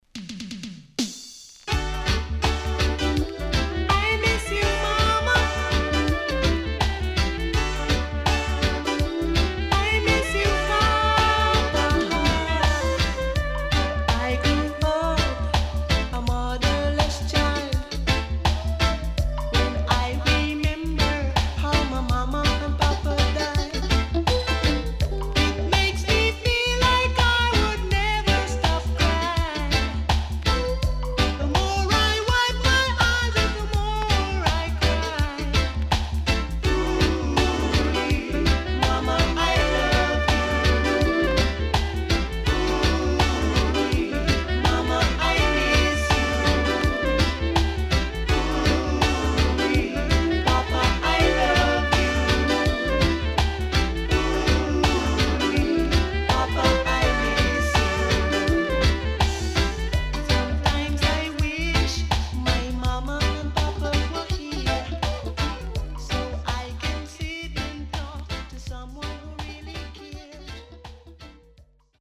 SIDE A:所々チリノイズ入りますが良好です。